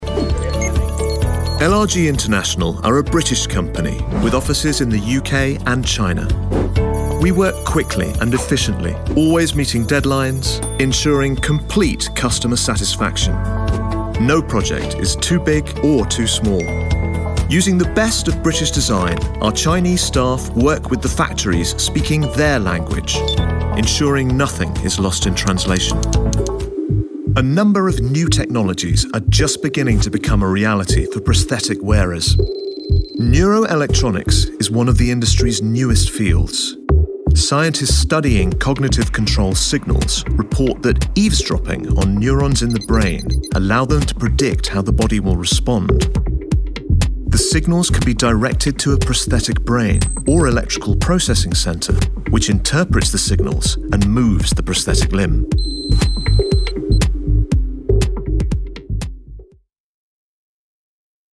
Male
Warm, convincing, trustworthy voice and genuine RP (Received Pronunciation) accent
Corporate
Profile And Explainer